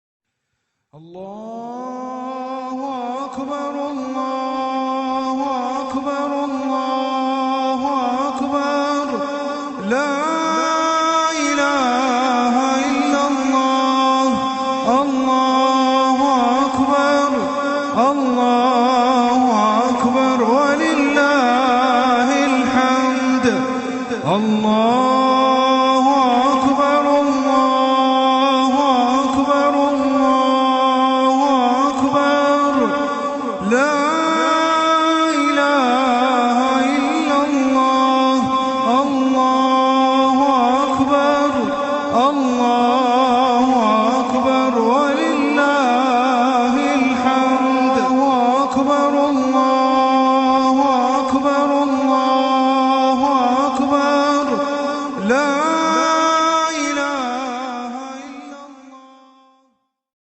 وتُعد تكبيرات العيد من أبرز الشعائر التي تميز هذا الموسم، إذ تُردد بأصوات مرتفعة منذ فجر يوم عرفة حتى عصر آخر أيام التشريق، في أجواء تفيض بالخشوع والبهجة.